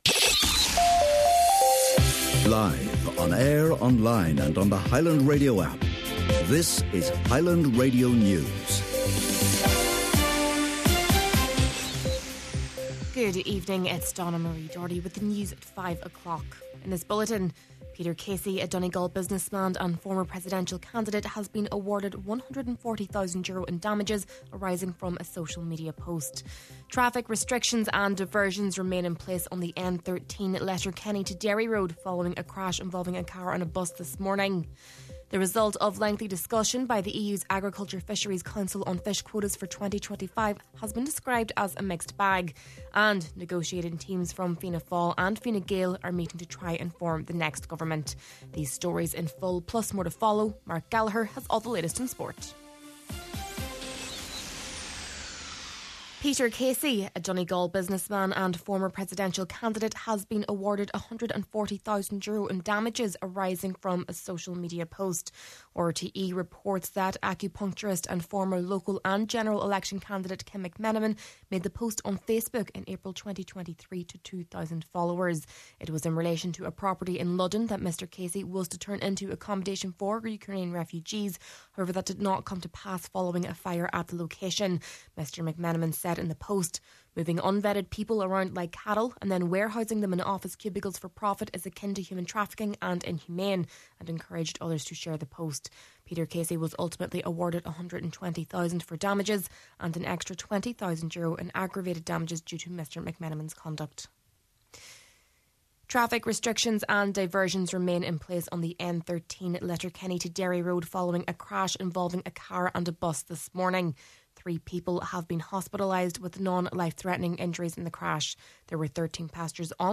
Main Evening News, Sport and Obituaries – Wednesday December 11th